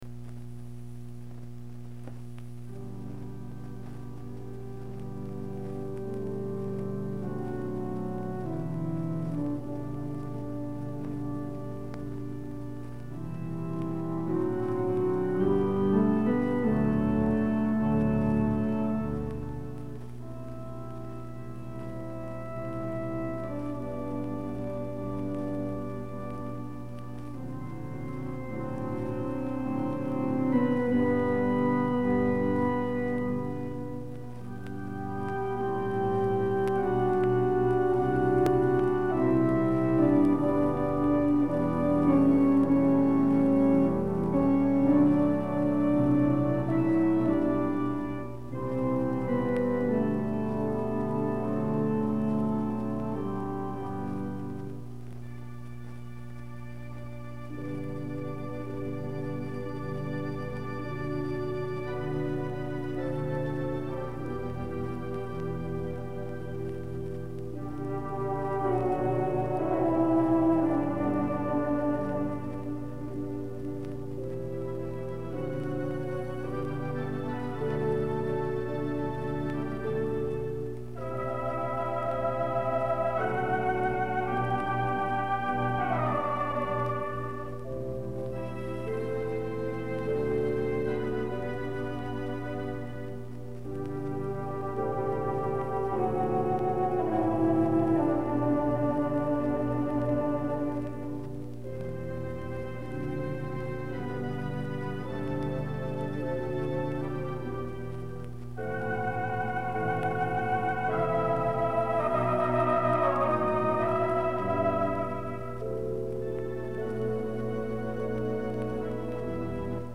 recorded on the Simonton Grande Wurlitzer